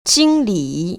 [jīnglĭ] 징리  ▶